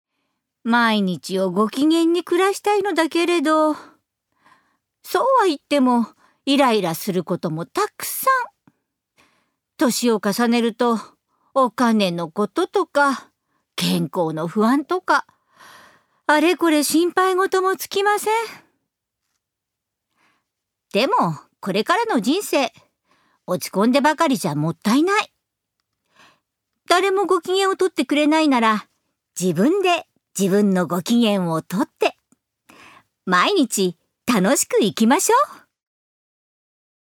ナレーション５